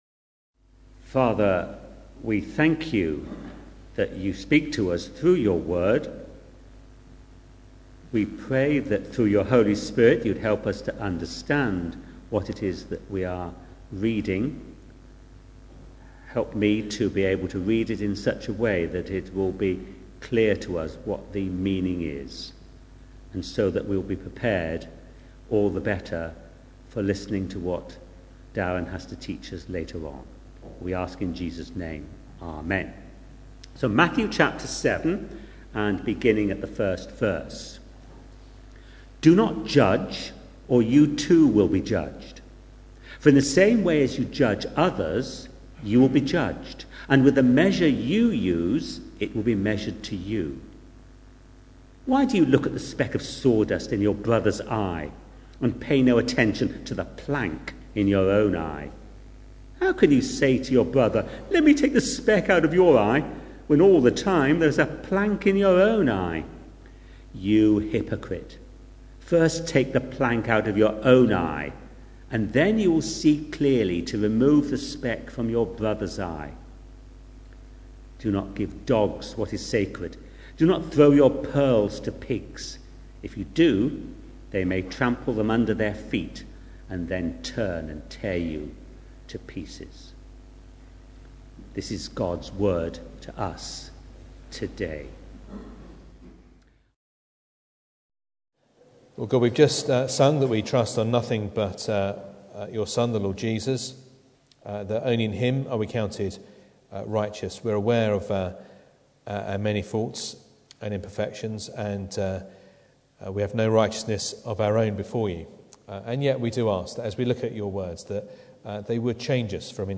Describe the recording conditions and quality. Matthew 7:1-6 Service Type: Sunday Morning Bible Text